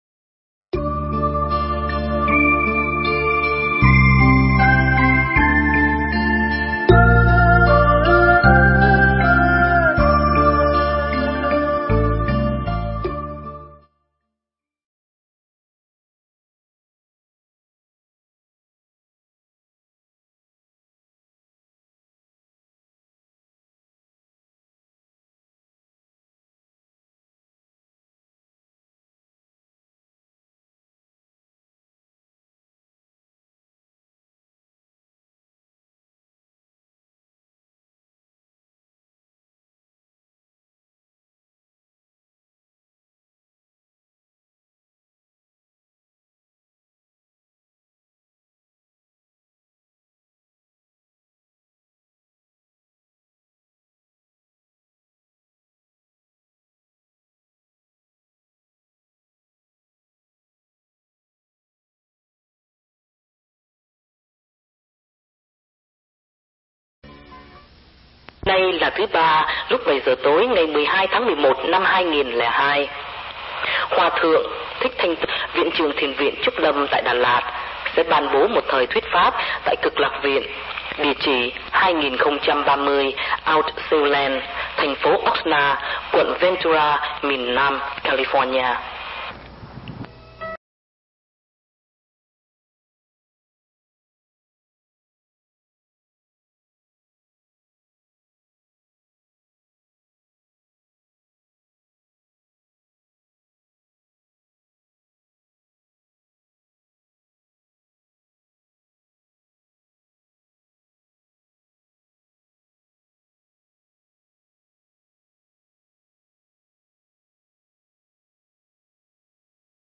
Thuyết pháp Nghiệp Báo – thầy Thích Thanh Từ mp3
Mp3 Thuyết Pháp Nghiệp Báo – Hòa Thượng Thích Thanh Từ giảng tại Cực Lạc Viên, ngày 12 tháng 11 năm 2002